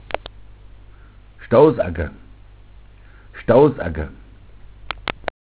Originaltext: Zum Namen der an der Straße und Ortschaft anstoßenden, an einem leichten Hang sich hinziehenden Stoßäcker (319 - 67), mundartlich der "Stouß", vergleiche stoss aufgeschichtetet Haufe, Bodenschwellung, -erhebung.
Stoffsammlung: Mundart: stouss_agga Internetsuchbegriff: stossacker Namenshäufigkeit: 85111 Möckenlohe, 85111 Ochsenfeld, 91809 Biesenhard, Zurück zu Flurnamen Ochsenfeld, bitte die Ochsen anklicken.